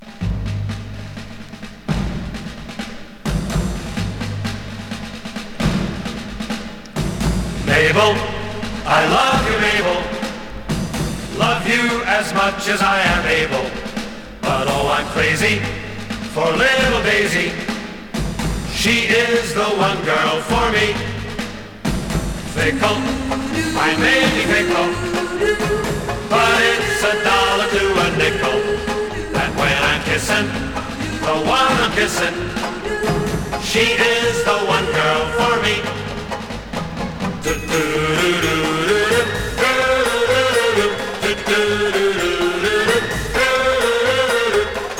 4人組男性ジャズコーラスグループ
彼らのイマジネイティブなボーカルも楽しく、爽快さも有り。
Pop, Vocal　USA　12inchレコード　33rpm　Stereo